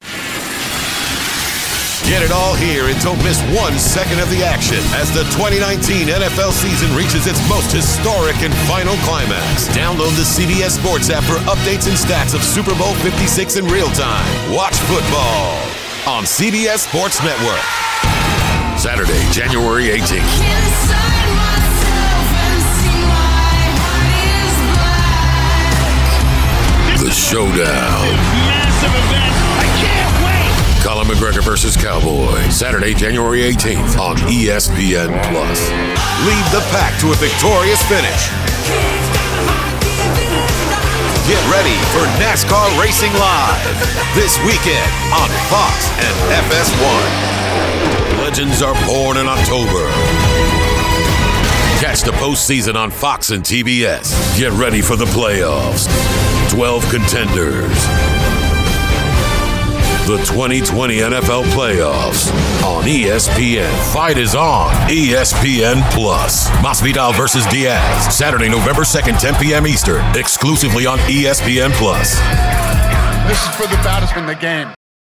Authoritative, Razor Sharp, Witty and Conversational
Sports Promo
Southern, NYC
Middle Aged